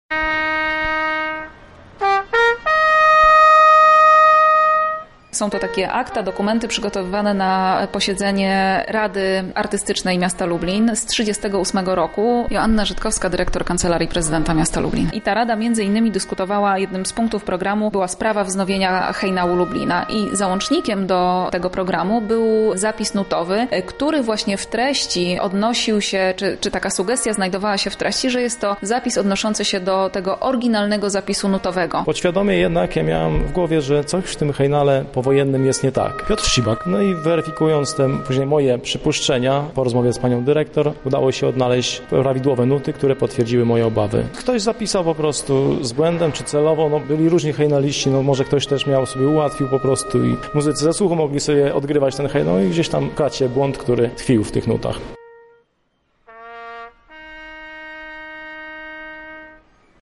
Hejnał